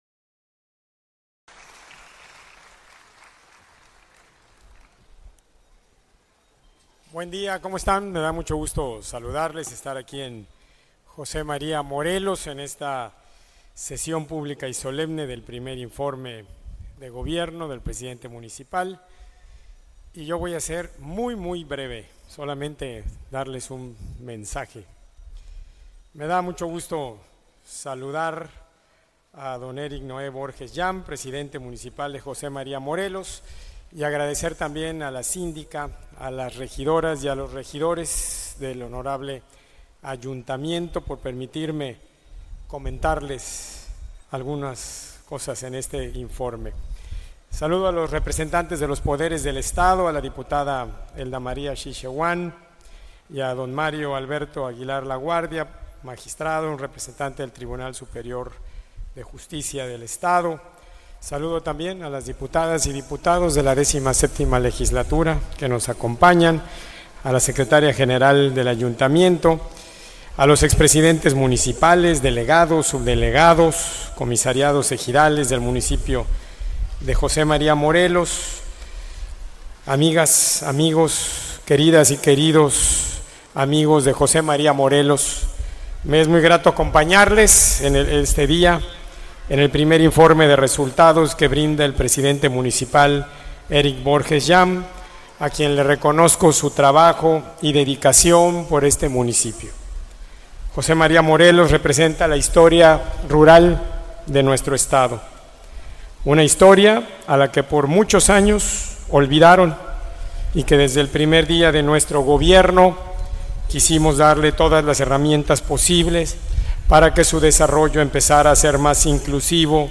((AUDIO)) Mensaje del gobernador Carlos Joaquín en el I Informe de Gobierno de José Maria Morelos.
AUDIO-Mensaje-del-gobernador-Carlos-Joaquin-en-el-I-Informe-de-Gobierno-de-Jose-Maria-Morelos..mp3